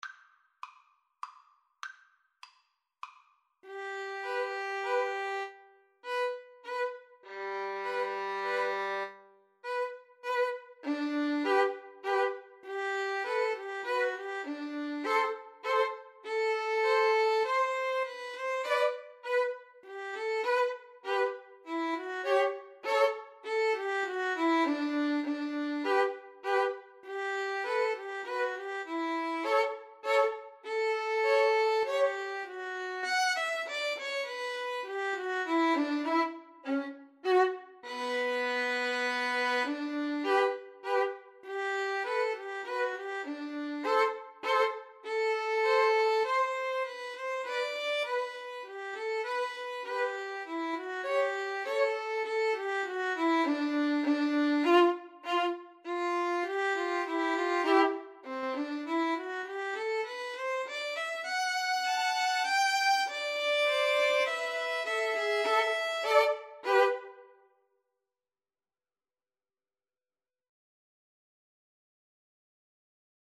3/4 (View more 3/4 Music)
Slowly = c.100
Classical (View more Classical Violin Trio Music)